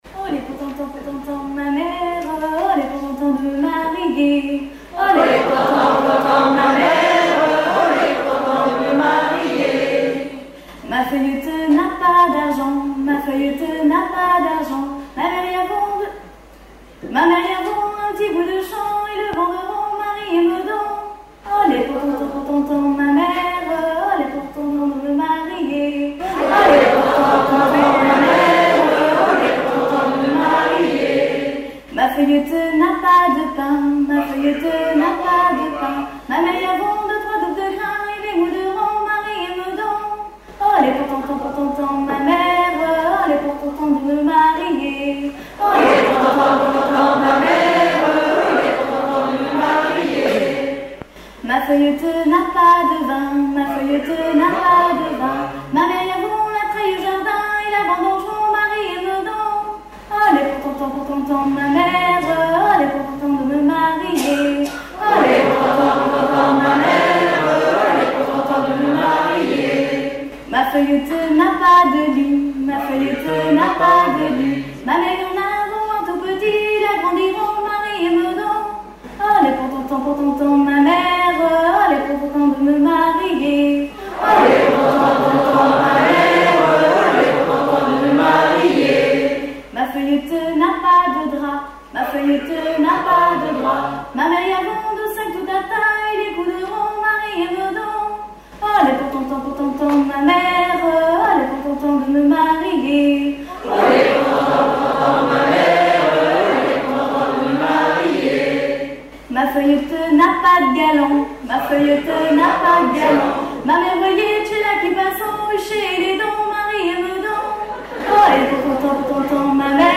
Genre dialogue
Festival de la chanson pour Neptune F.M.
Pièce musicale inédite